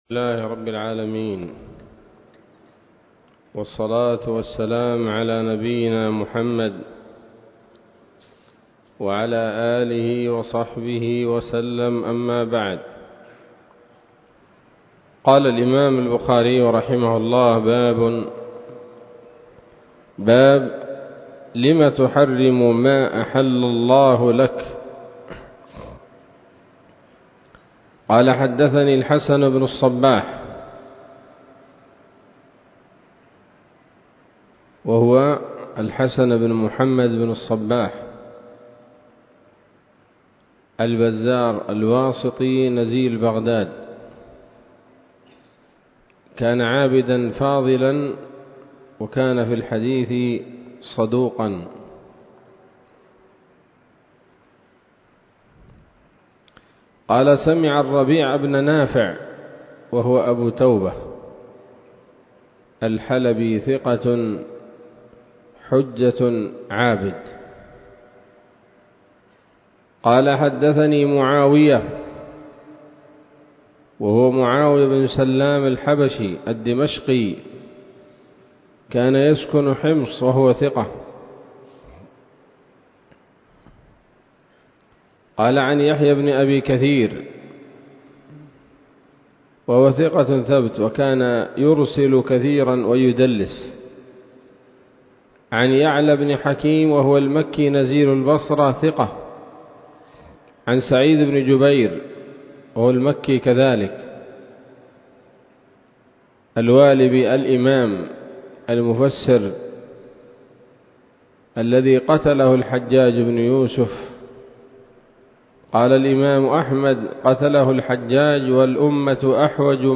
الدرس السابع من كتاب الطلاق من صحيح الإمام البخاري